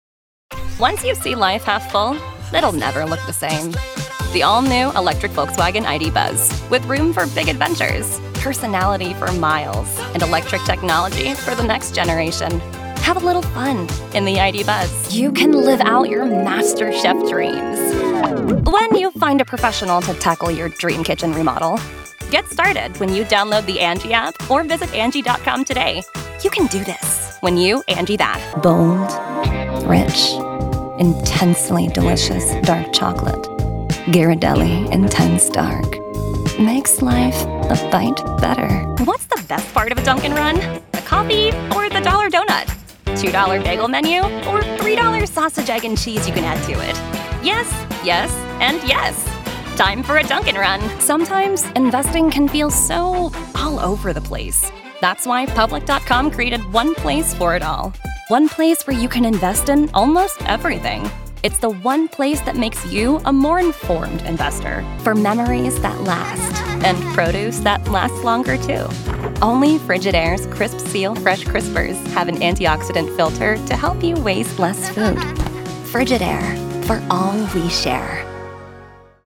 Anglais (Américain)
Distinctive, Cool, Polyvalente, Fiable, Amicale
Commercial